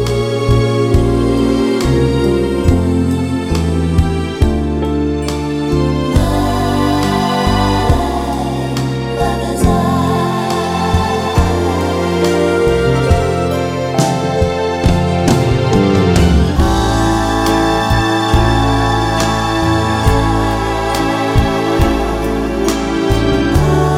Crooners